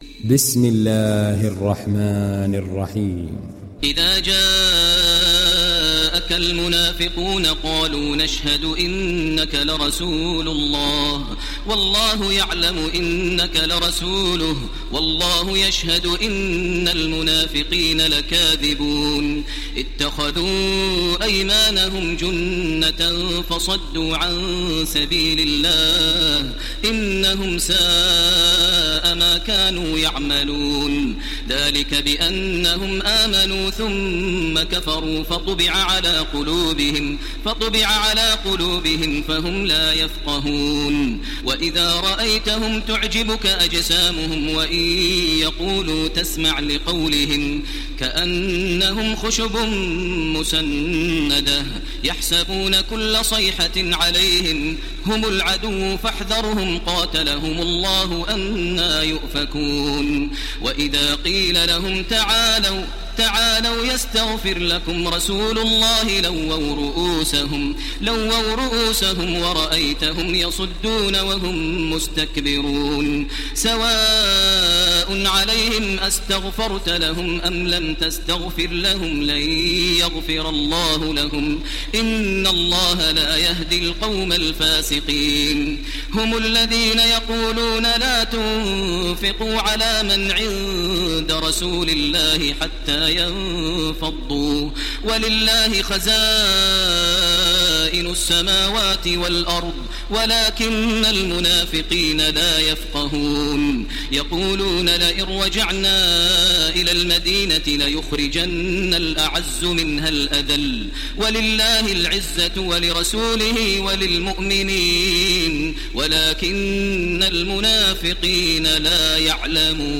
Télécharger Sourate Al Munafiqun Taraweeh Makkah 1430